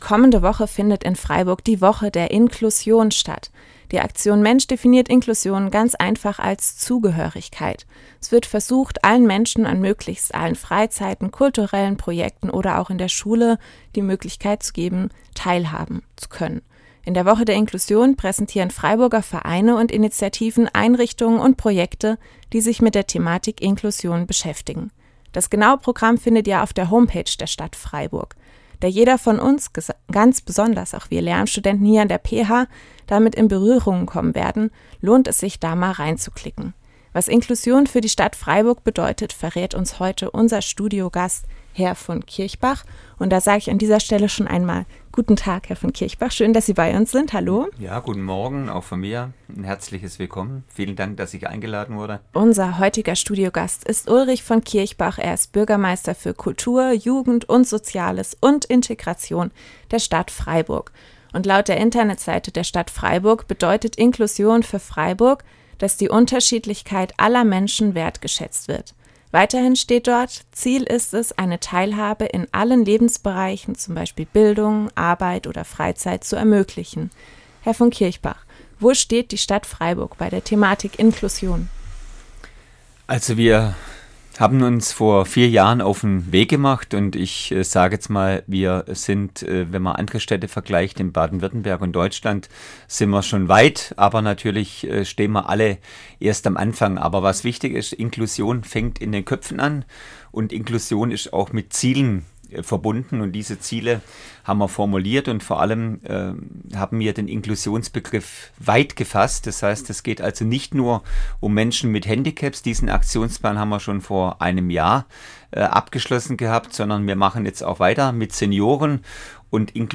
Interview
Passend zum Start der Inklusionswoche war Sozialbürgermeister Ulrich von Kirchbach im Studio der PH zu Gast.